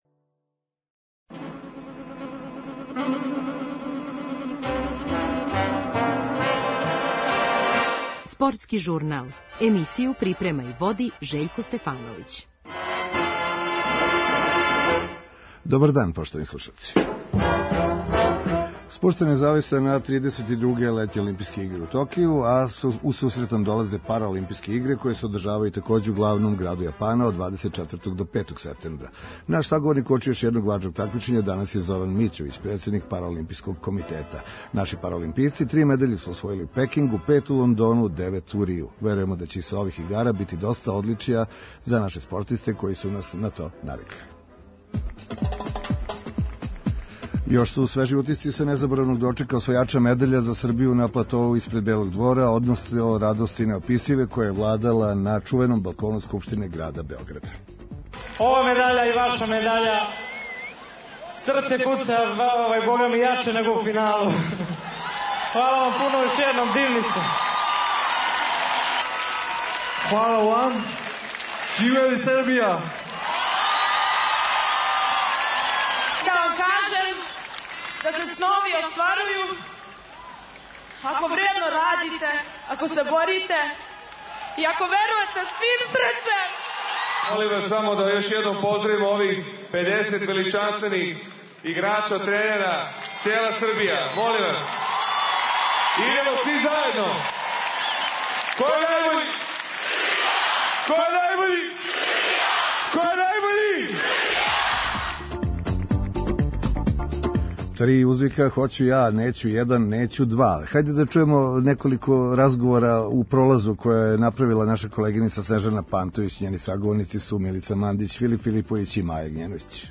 Данас ћемо чути разговоре са Милицом Мандић и одбојкашицом Мајом Огњеновић.